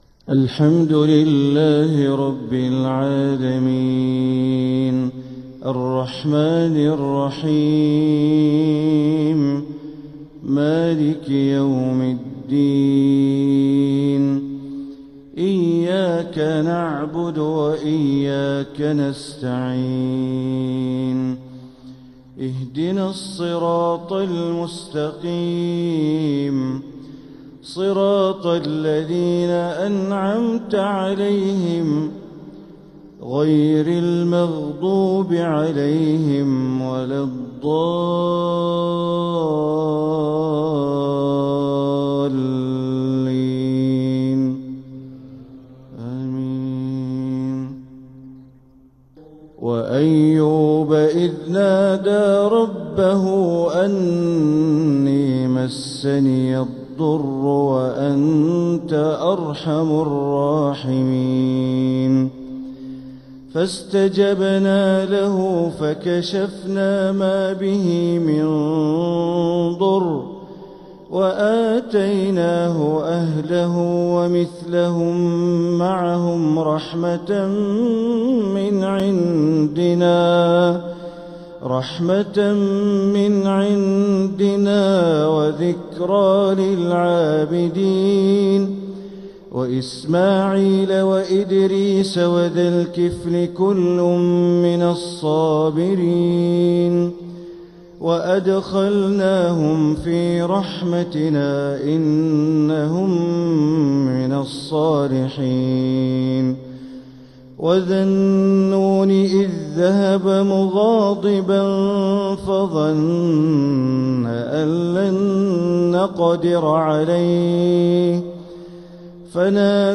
Fajr prayer from Surah Al-Anbiya 1-5-2025 > 1446 > Prayers - Bandar Baleela Recitations